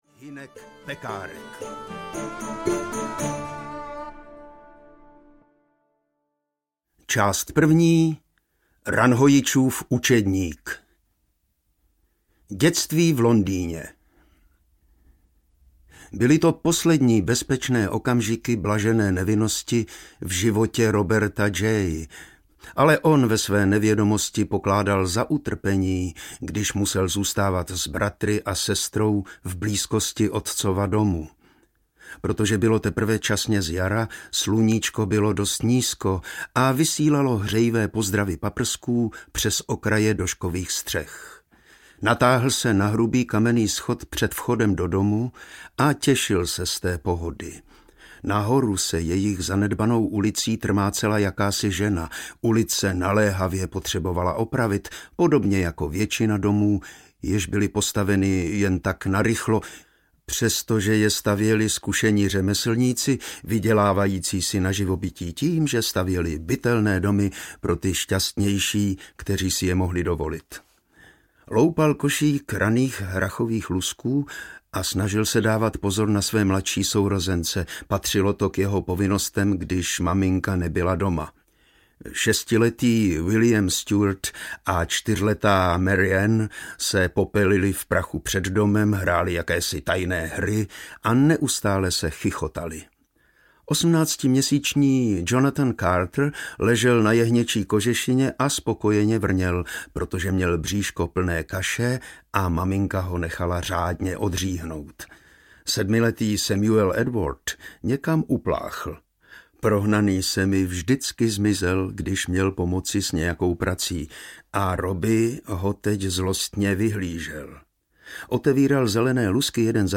Ranhojič audiokniha
Ukázka z knihy
• InterpretHanuš Bor